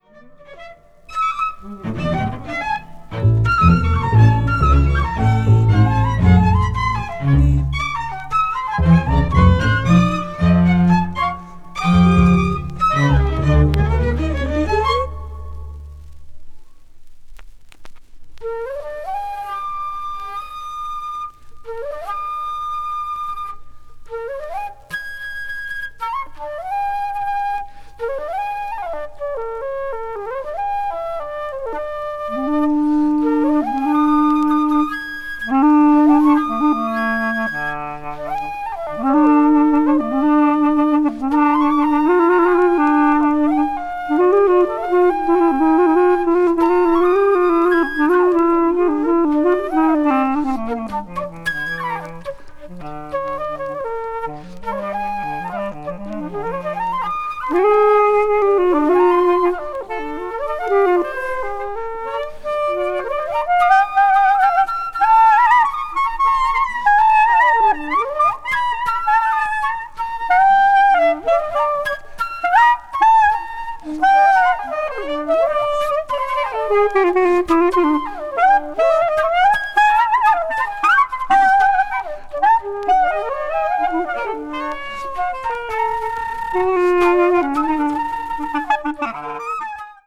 Recorded in New York
a nine-member ensemble
vocals
clarinet
alto saxophone
avant-jazz   free improvisaton   free jazz   spiritual jazz